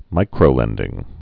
(mīkrō-lĕndĭng)